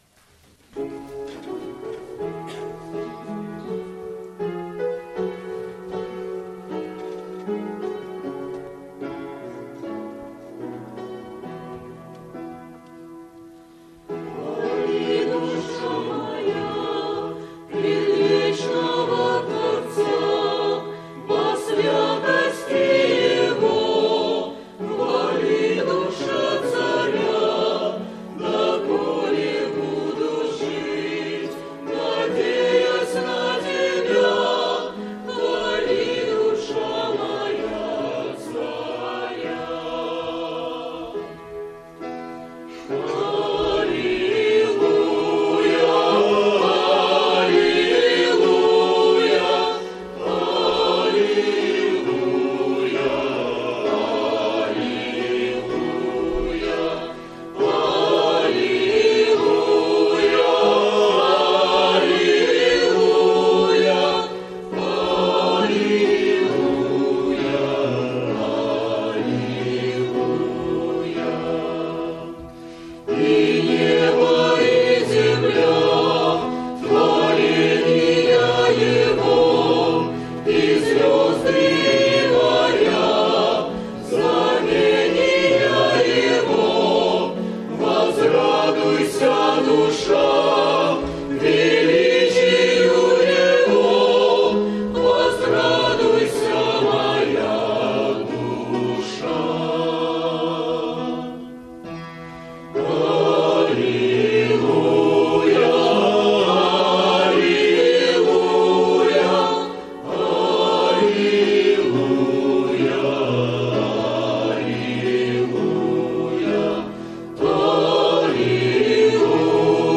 Богослужение 12.09.2010 mp3 видео фото
Хвали душа моя - Хор (Пение)